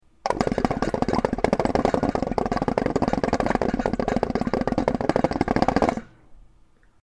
Звуки кальяна
Шум кальянного курения